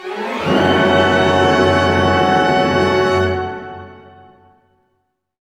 Index of /90_sSampleCDs/Roland - String Master Series/ORC_Orch Gliss/ORC_Minor Gliss